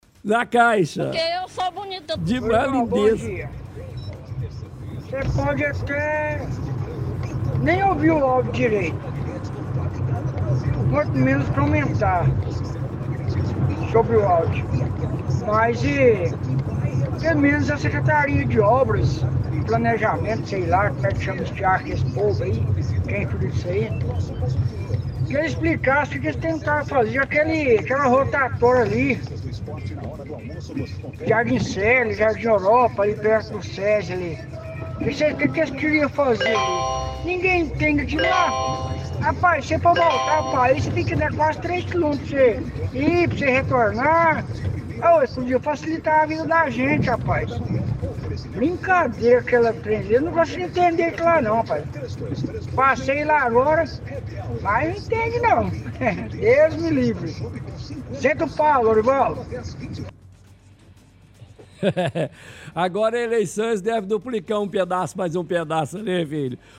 – Ouvinte reclama de rotatório no Jardim Célia, dizendo que não entende o que queriam fazer no local.